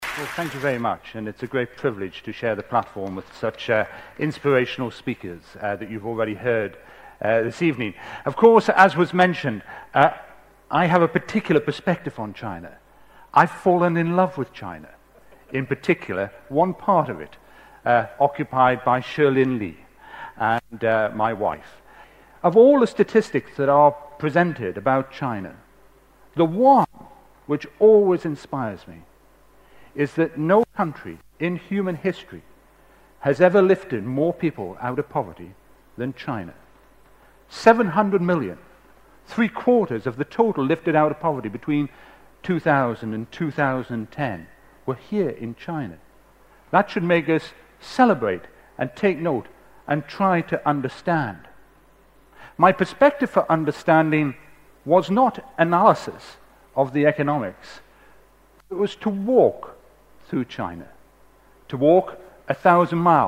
TED演讲:我难忘的一次中国行(1) 听力文件下载—在线英语听力室